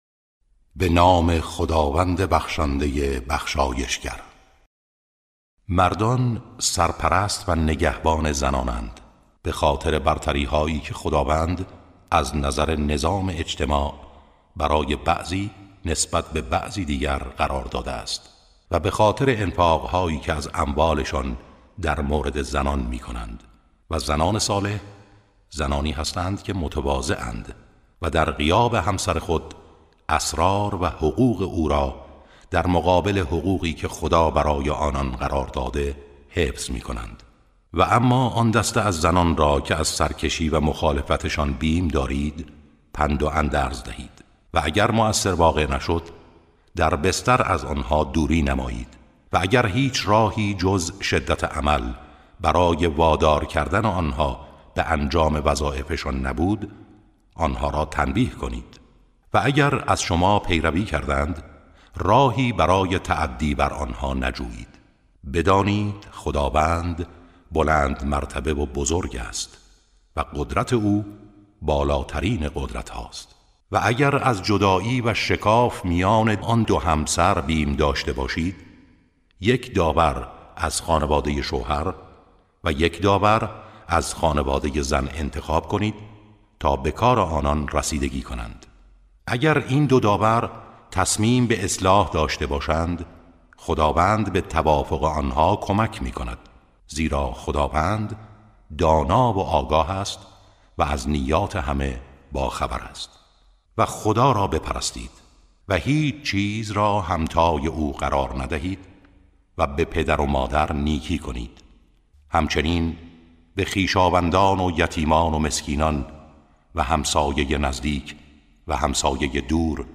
ترتیل صفحه ۸۴ سوره مبارکه نساء(جزء پنجم)